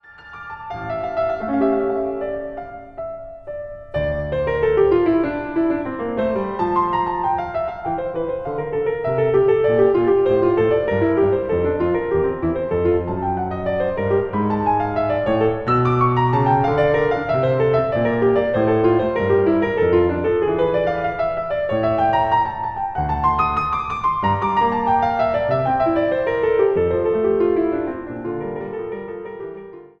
24 preludes voor piano